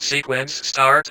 VVE1 Vocoder Phrases 09.wav